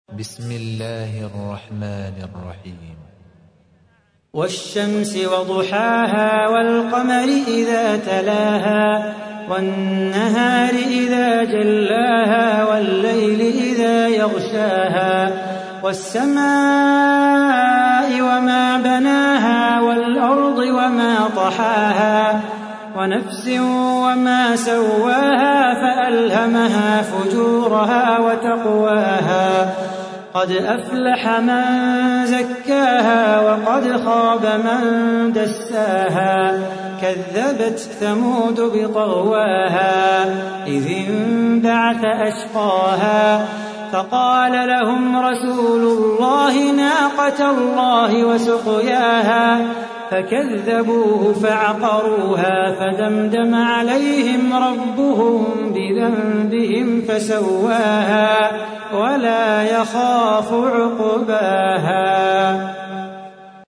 تحميل : 91. سورة الشمس / القارئ صلاح بو خاطر / القرآن الكريم / موقع يا حسين